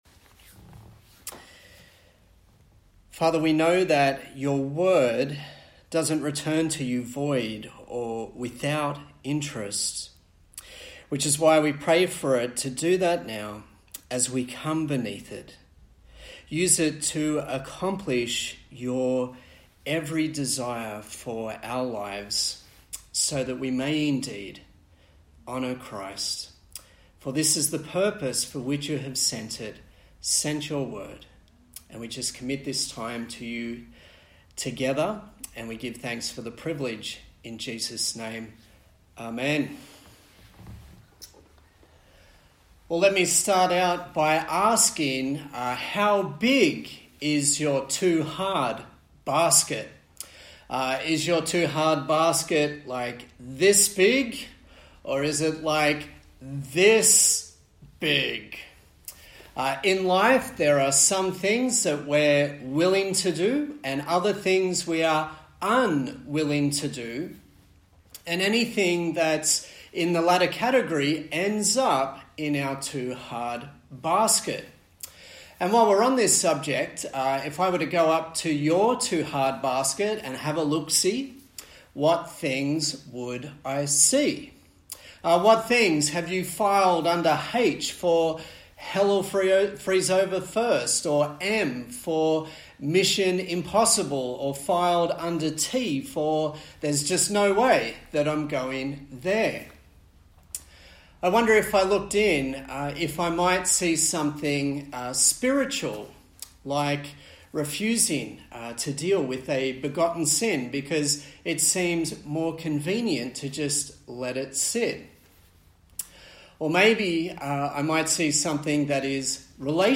A sermon in the series on the book of Ecclesiastes